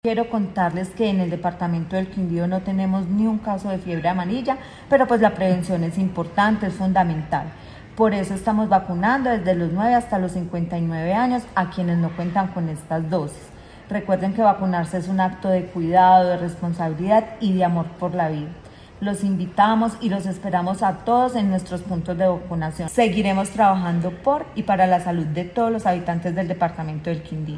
Secretaria de Salud del Quindío